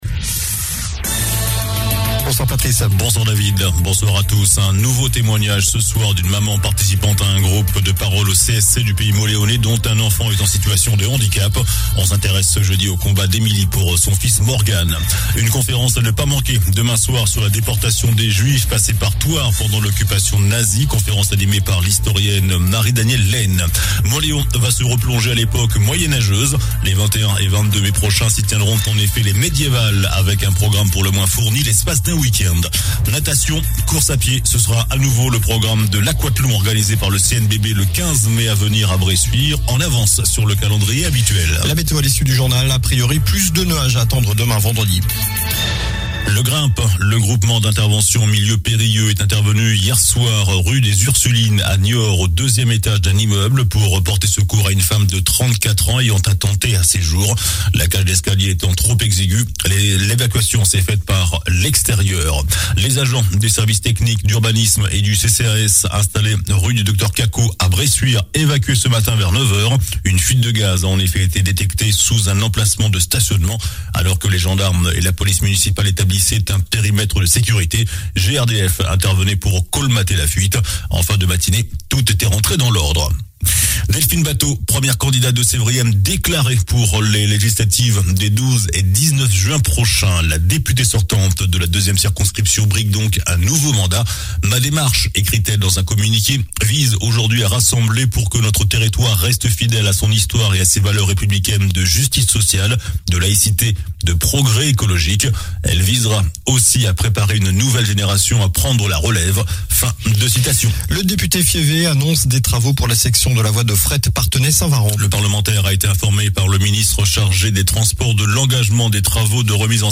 JOURNAL DU JEUDI 28 AVRIL ( SOIR )